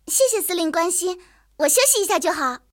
野牛小破修理语音.OGG